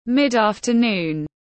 Giữa giờ chiều tiếng anh gọi là mid-afternoon, phiên âm tiếng anh đọc là /mɪd ˌɑːf.təˈnuːn/